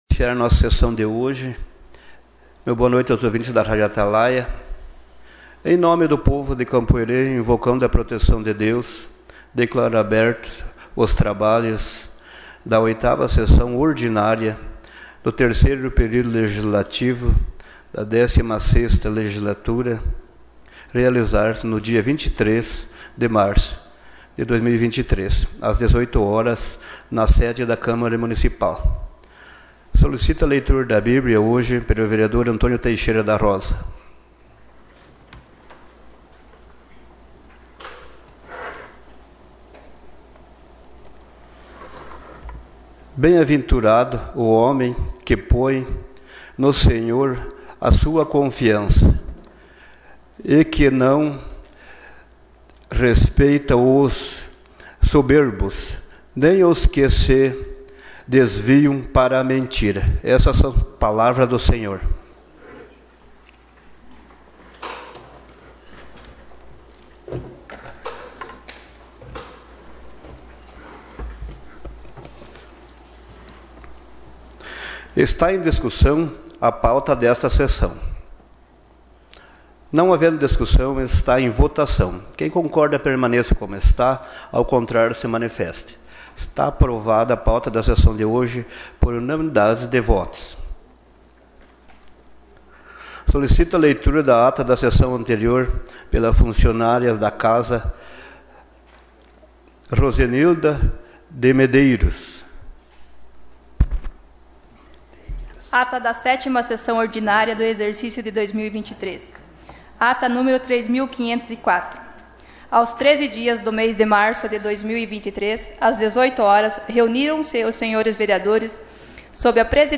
Gravação das Sessões